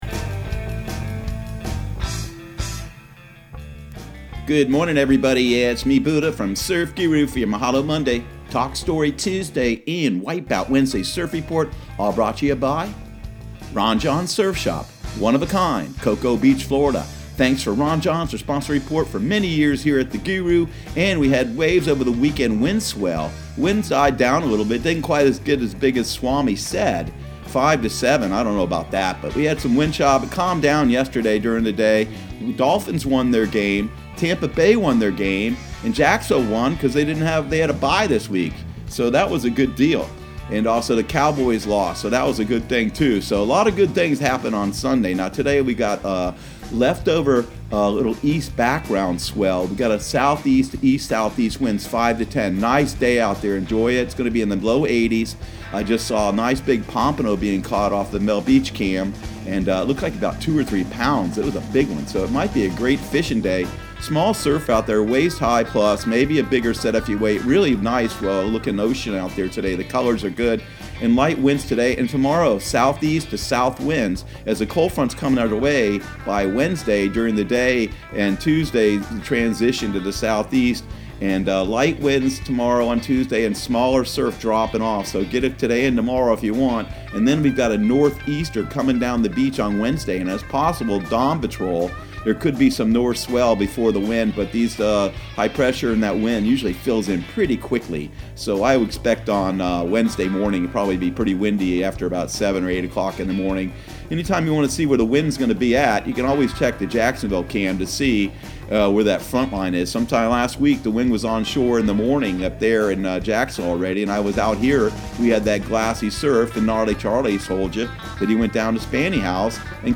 Surf Guru Surf Report and Forecast 11/12/2019 Audio surf report and surf forecast on November 12 for Central Florida and the Southeast.